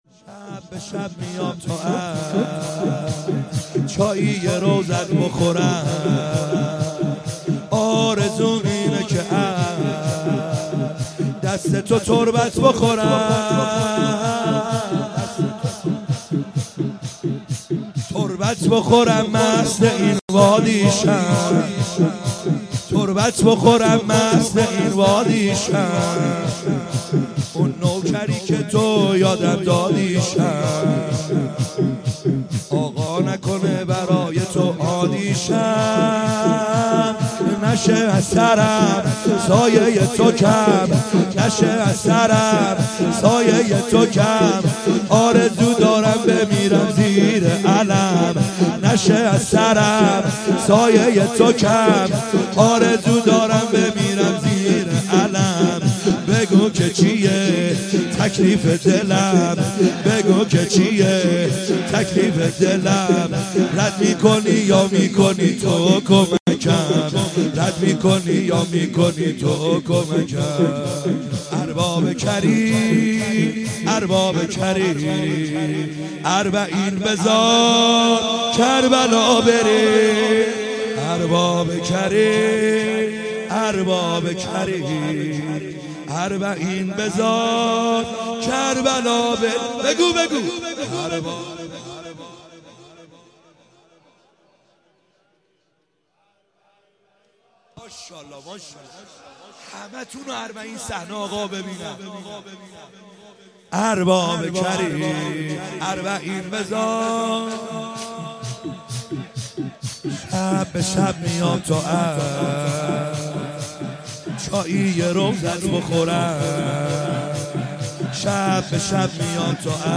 خیمه گاه - بیرق معظم محبین حضرت صاحب الزمان(عج) - شور | شب به شب